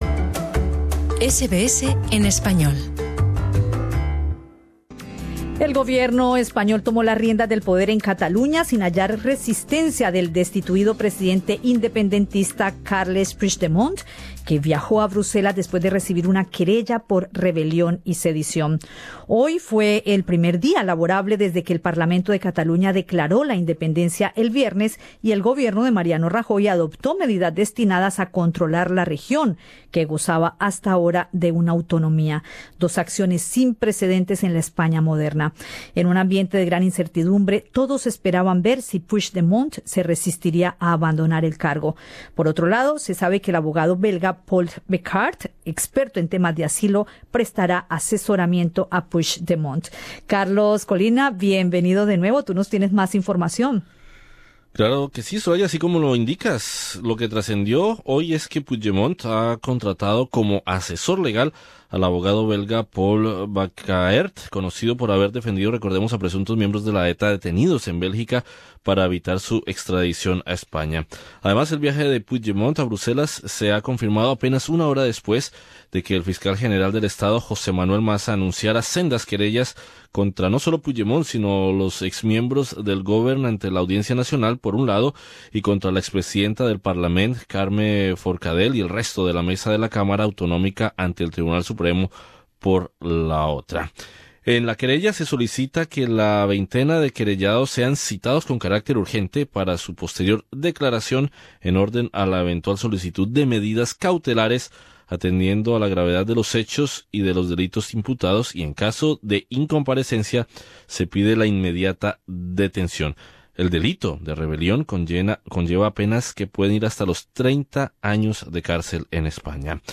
Entrevista con el alcalde de Alella en Cataluña, Andreu Francisco.